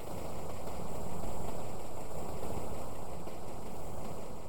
TowerDefenseGame/Rain.wav at 67aa38b5d248a8ee049be1b9373bf13f82cc520b
Rain.wav